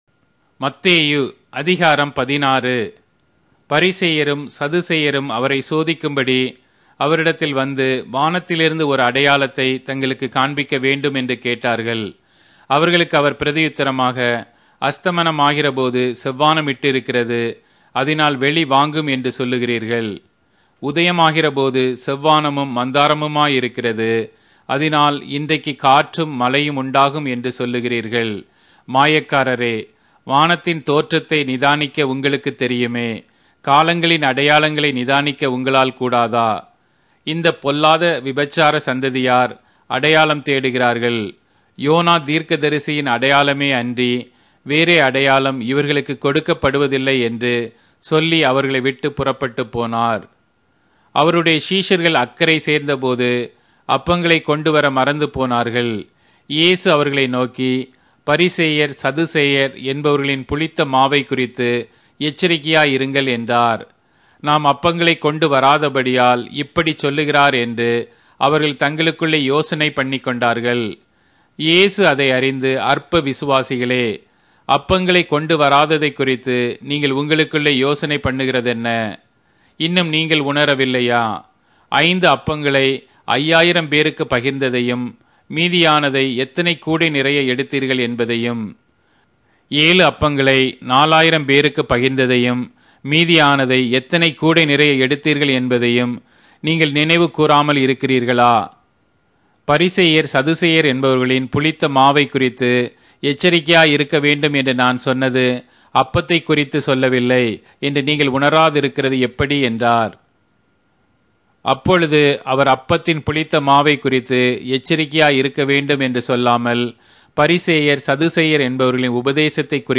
Tamil Audio Bible - Matthew 10 in Knv bible version